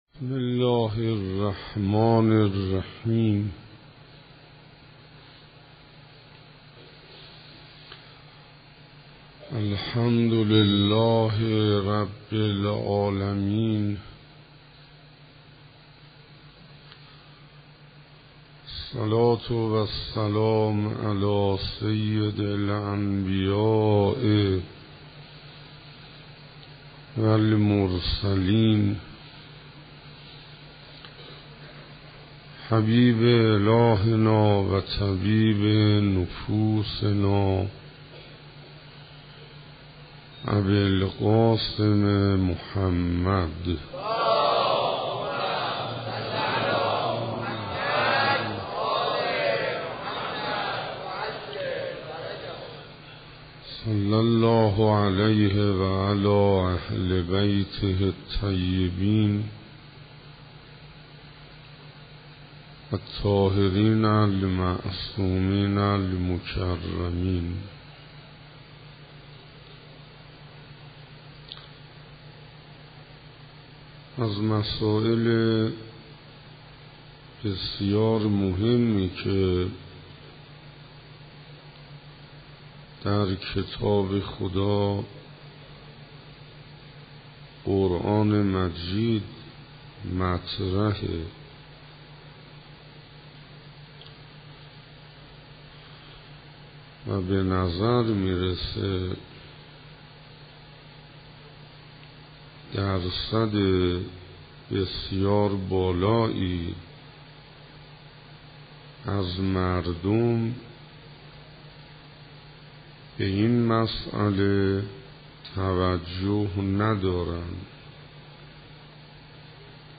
کرمانشاه دههٔ اوّل صفر 95 سخنرانی اوّل_نشانه های مومن